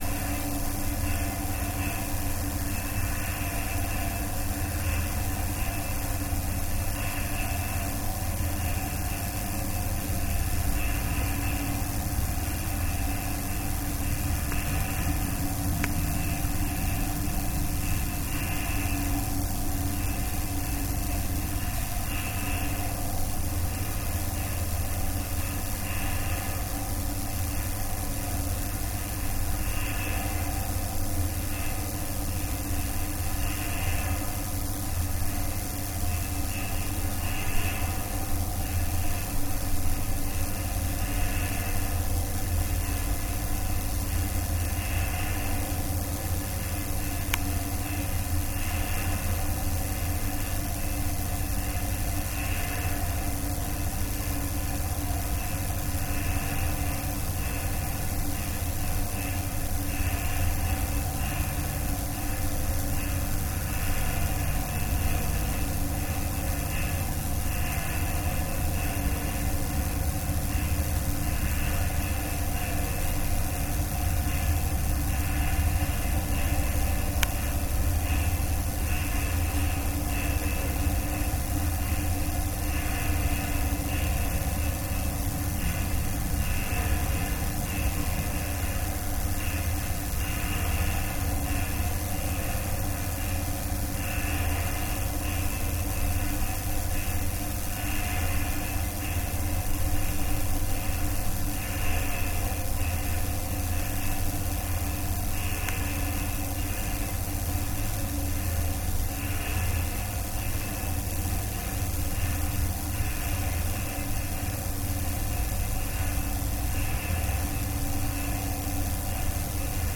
Transformer station, Bjert, Denmark